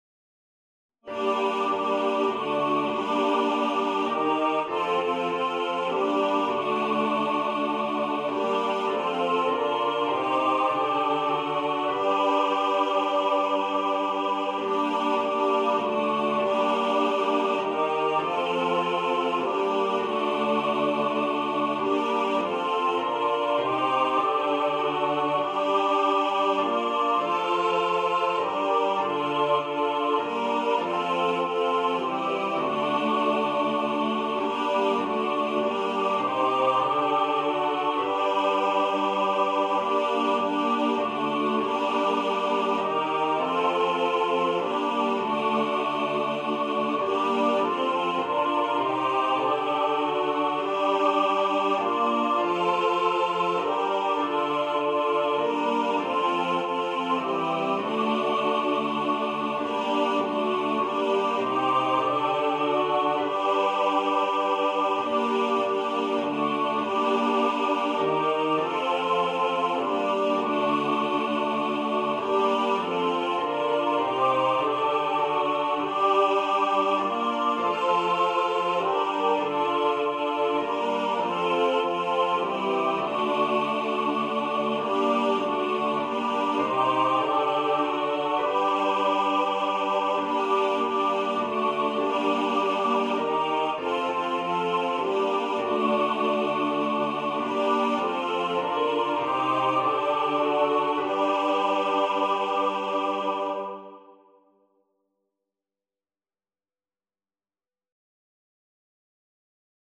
Coventry-Carol-All-Voices.mp3